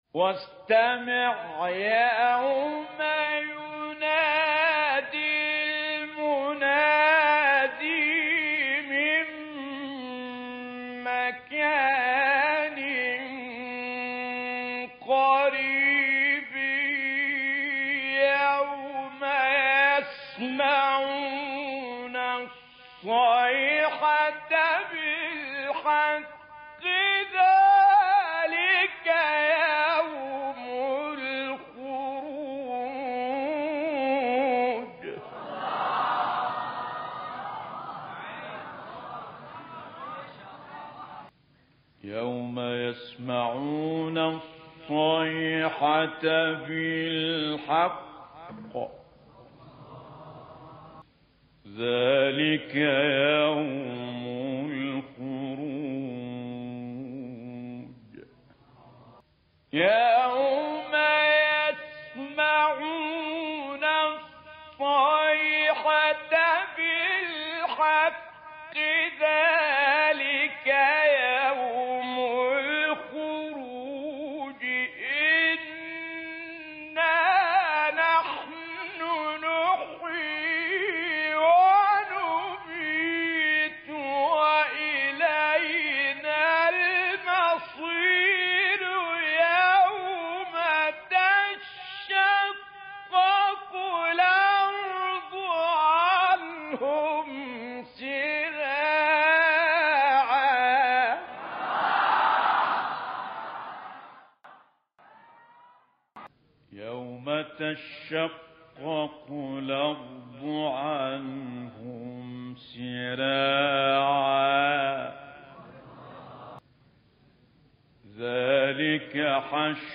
سوره : ق – قریش آیه: (41-45) – (1-3) استاد : متولی عبدالعال مقام : مرکب خوانی(سه گاه * بیات) قبلی بعدی